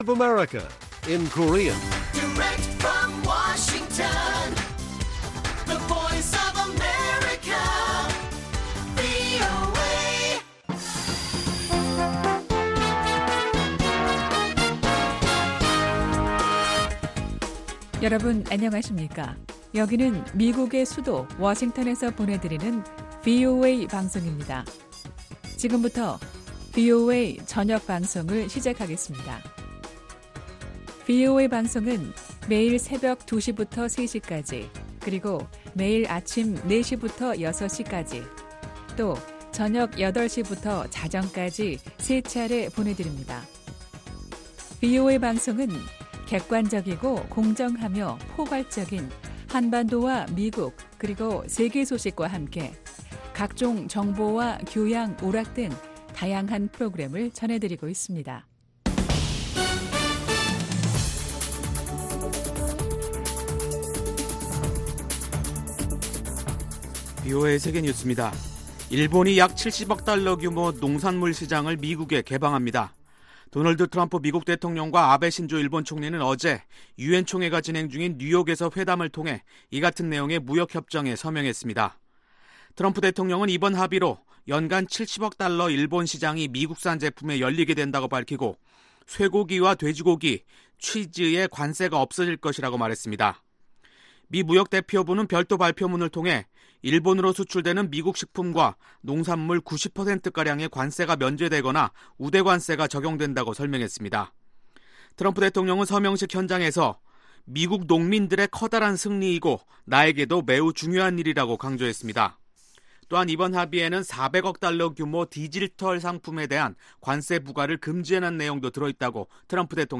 VOA 한국어 간판 뉴스 프로그램 '뉴스 투데이', 2019년 9월 26일 1부 방송입니다. 북한의 ‘단계적 비핵화’가 성공하려면 북한 핵시설의 투명한 신고가 선결돼야 한다고 미국 상원 민주당의원들이 밝혔습니다. 한-일 양국의 갈등은 동북아 평화와 번영을 수호하려는 미-한-일 3자 협력을 저해한다고 미 국무부 한국-일본 담당 동아태 부차관보가 말했습니다.